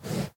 horse_breathe3.ogg